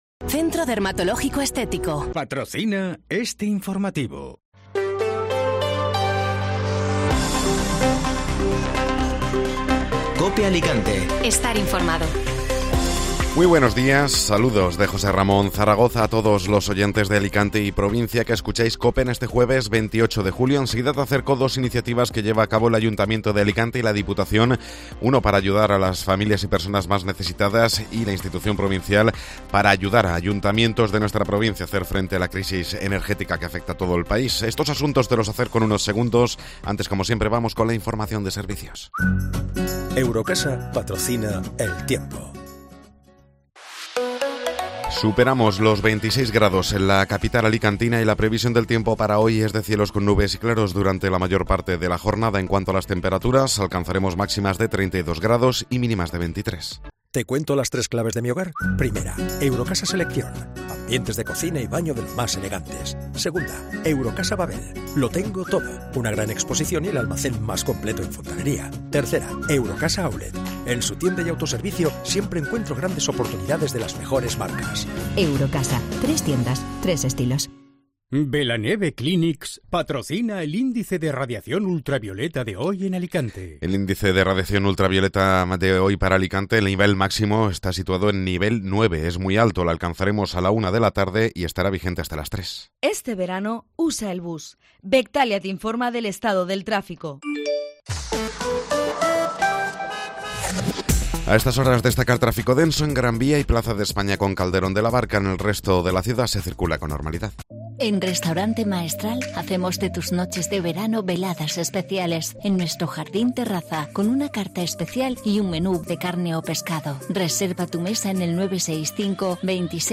Informativo Matinal (Jueves 28 de Julio)